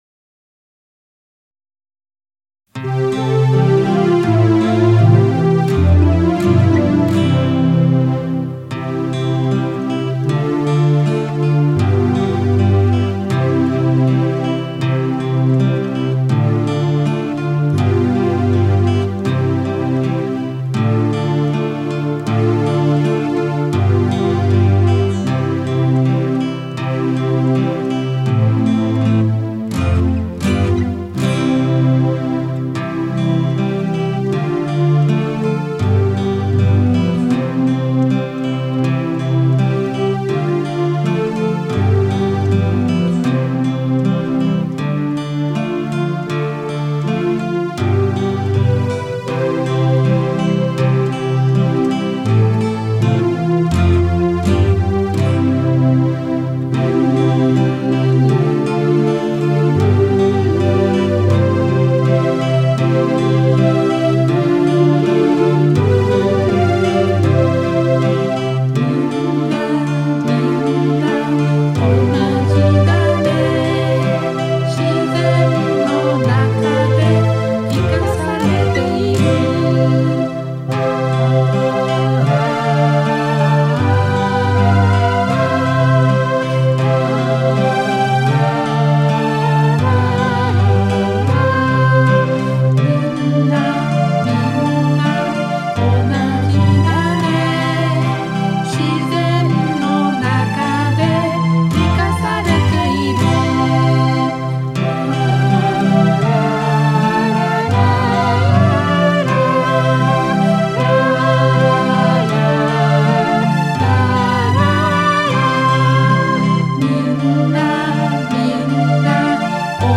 カラオケ音源 (ガイドメロディなし) をダウンロード